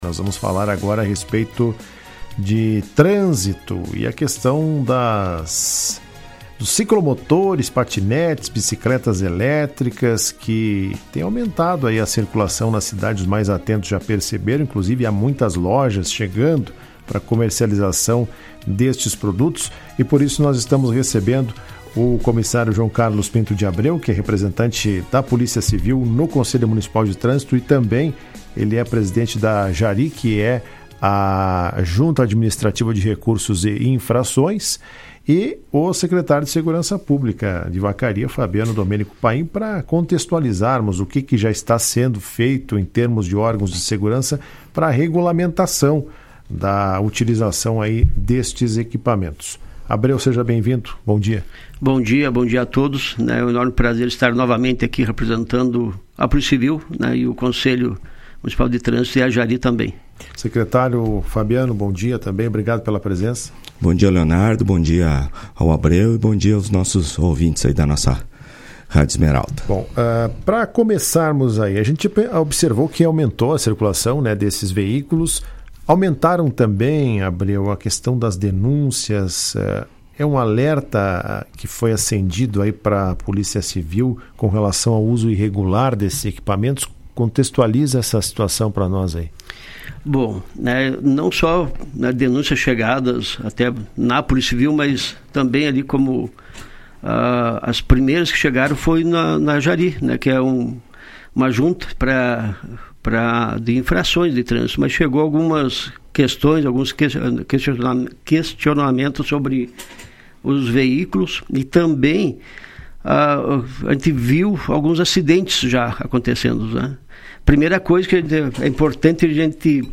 Na manhã desta terça-feira, o programa Comando Geral, da Rádio Esmeralda, promoveu um debate focado no uso irregular de ciclomotores, bicicletas e patinetes elétricas, equipamentos que se tornaram febre, especialmente entre os adolescentes, mas que vêm sendo utilizados de forma perigosa.